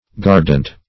Gardant \Gar"dant\, a.